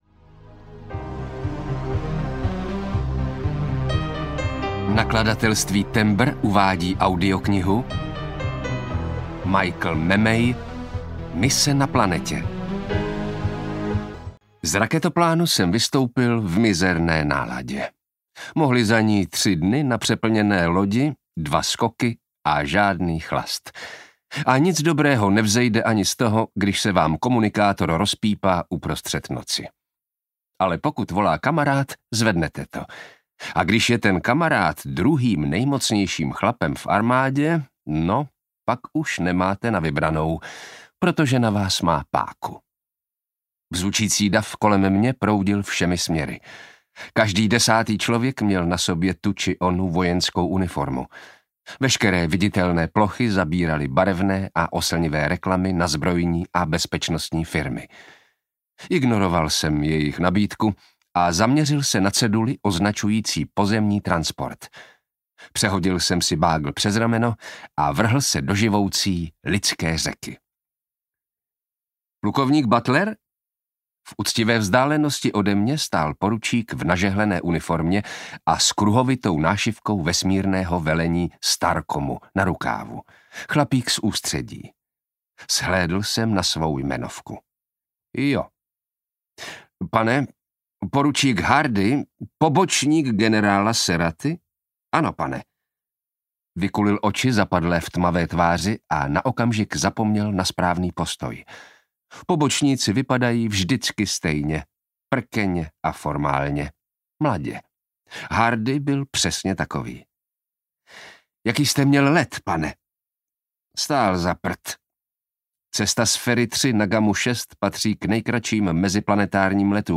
Mise na planetě audiokniha
Ukázka z knihy
Natočeno ve studiu S Pro Alfa CZ